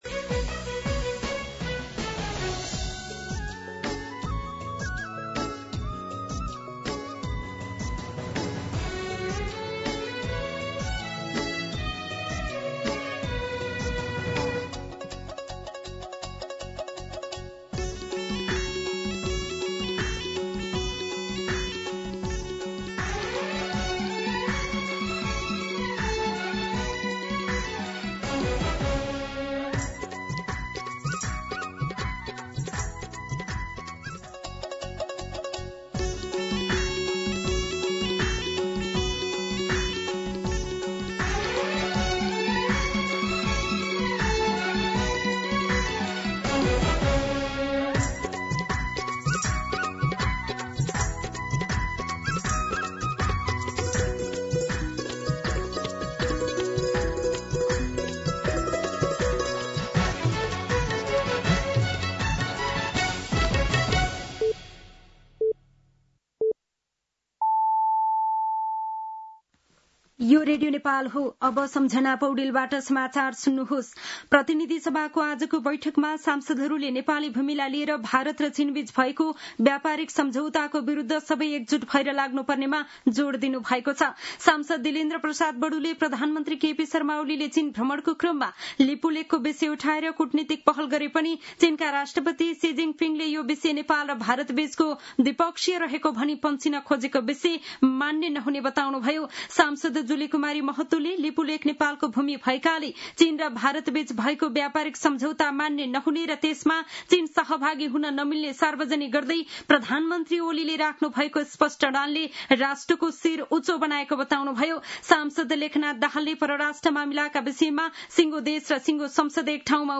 दिउँसो ४ बजेको नेपाली समाचार : १८ भदौ , २०८२
4pm-News-05-18.mp3